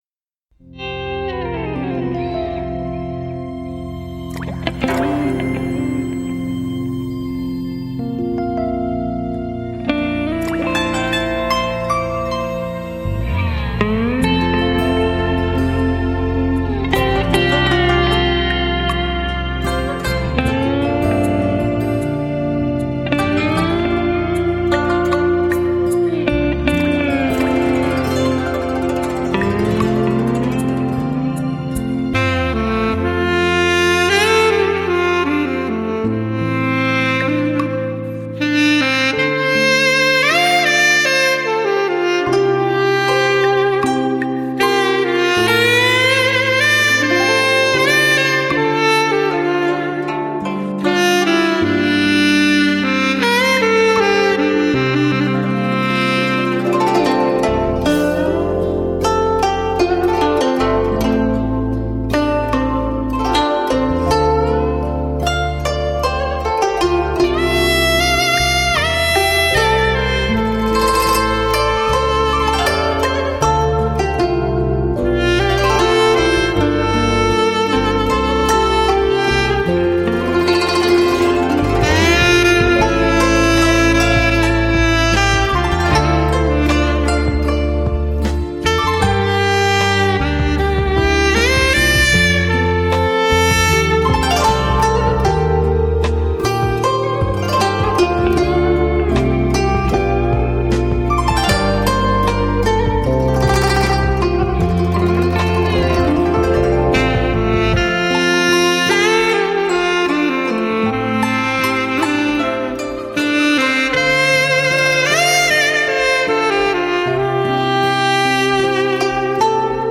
中西乐器对话